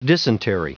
Prononciation du mot dysentery en anglais (fichier audio)